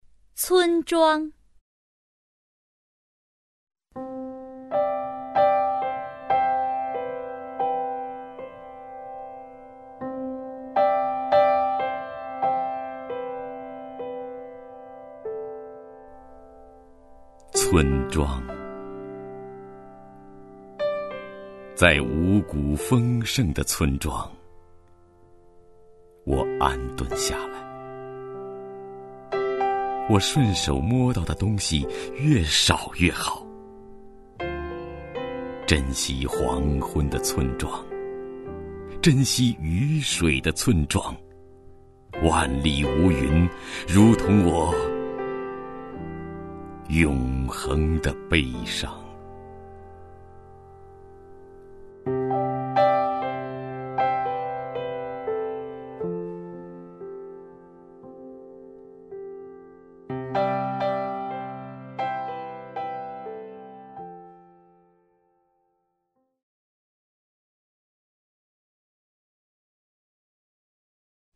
徐涛朗诵：《村庄》(海子) (右击另存下载) 村庄， 在五谷丰盛的村庄， 我安顿下来 我顺手摸到的东西越少越好！
名家朗诵欣赏